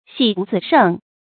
喜不自勝 注音： ㄒㄧˇ ㄅㄨˋ ㄗㄧˋ ㄕㄥˋ 讀音讀法： 意思解釋： 勝：承擔。